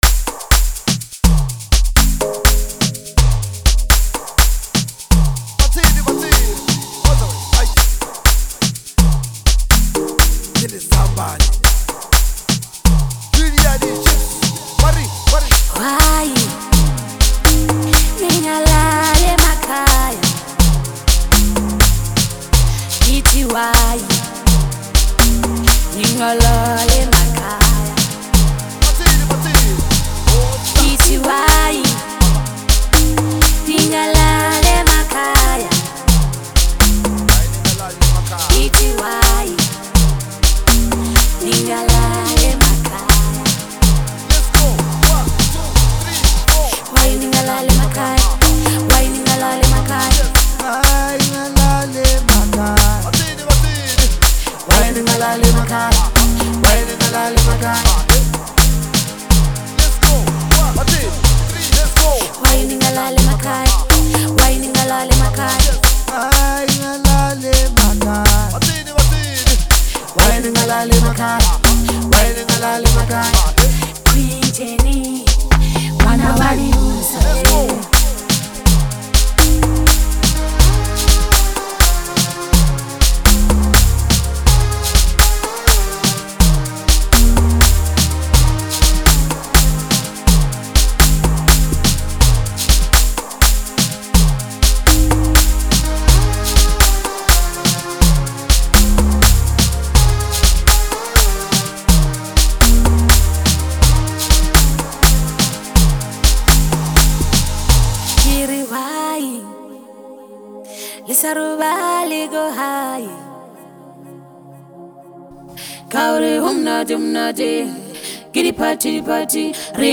amapiano beats